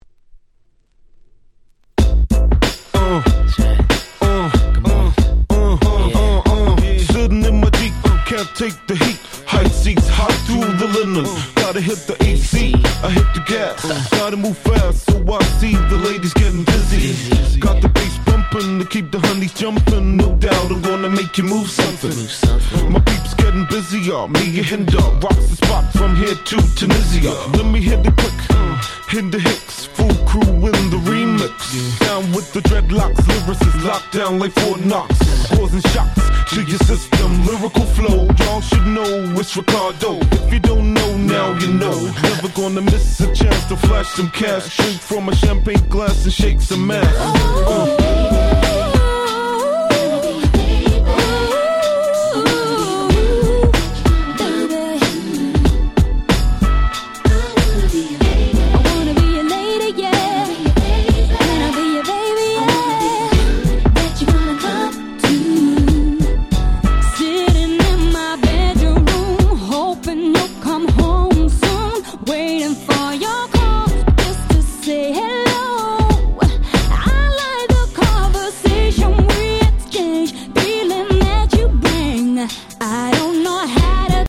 97' Smash Hit UK R&B / Slow Jam !!